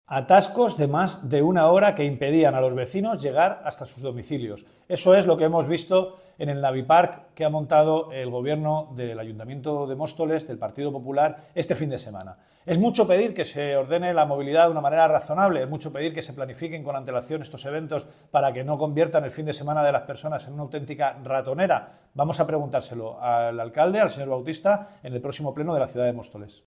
declaraciones-emilio-delgado-movilidad-navidad.mp3